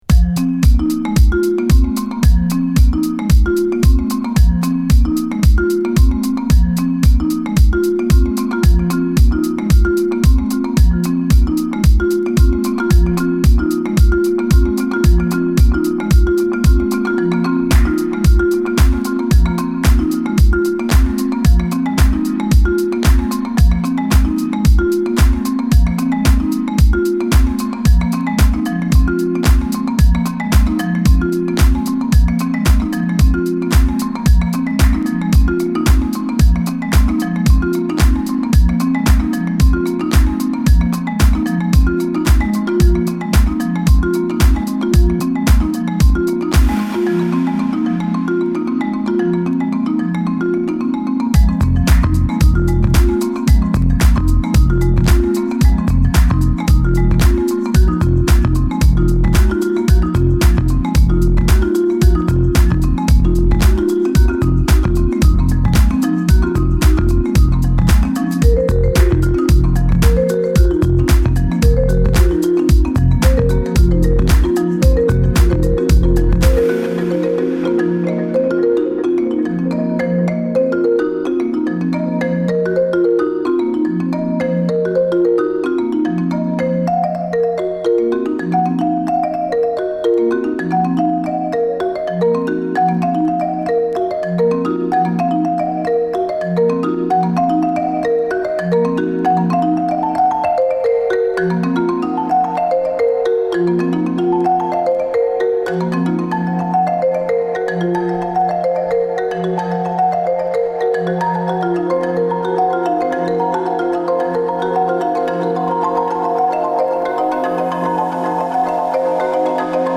ミッドテンポのブギー・ビートで緩急付けながら展開していく1曲で